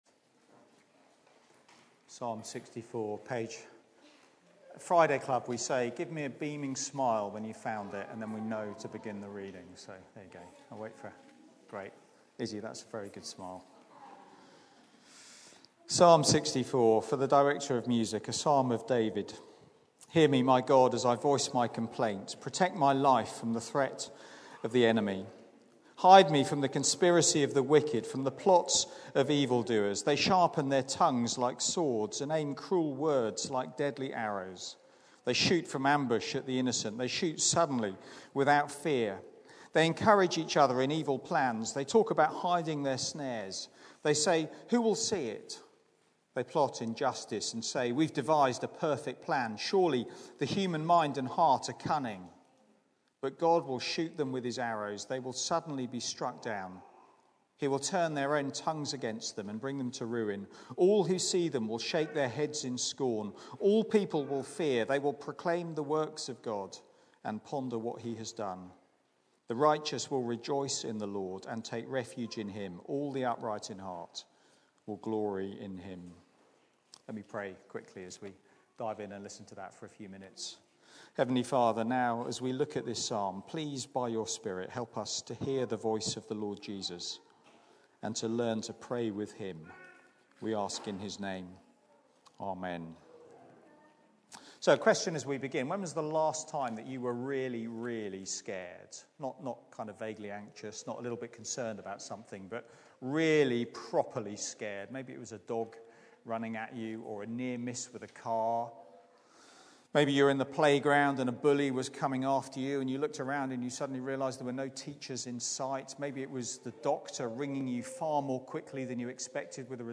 Church at the Green Sunday 4pm
Sermon